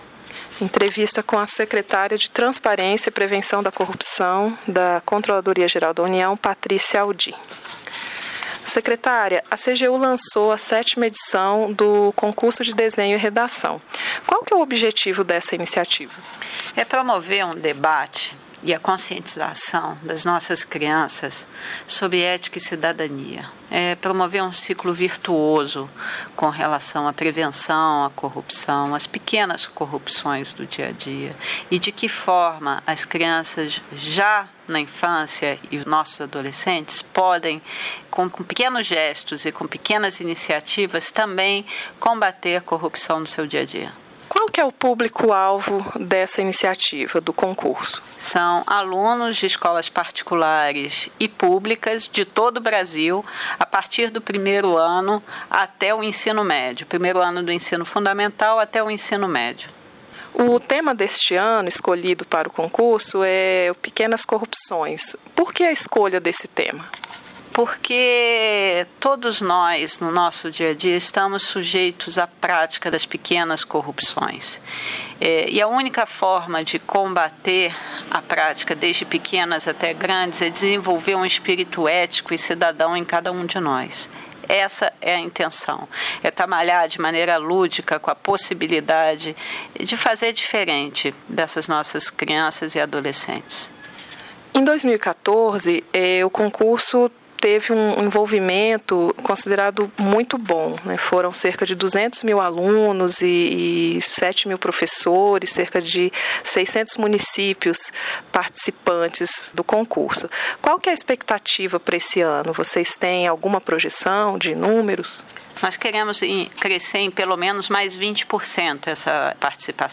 Entrevista: Patrícia Audi - Concurso de Desenho e Redação — Controladoria-Geral da União